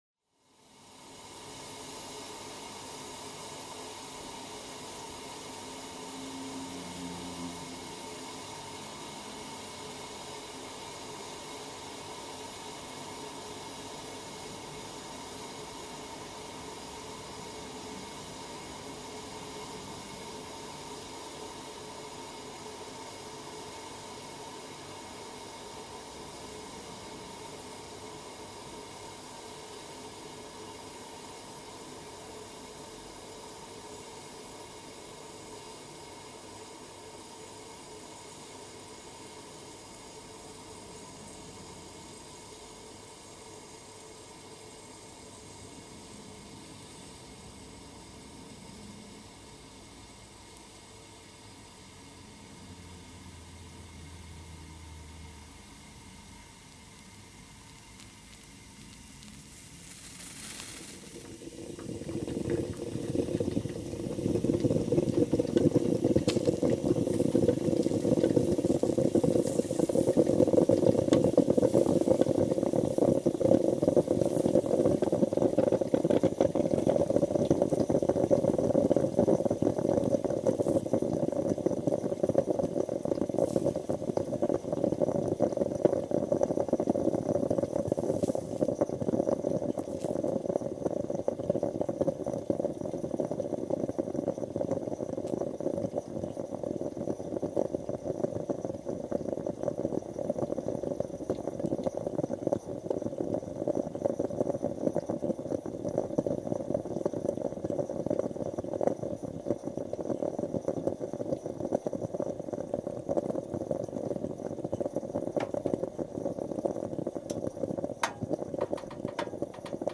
Italian stove top coffee maker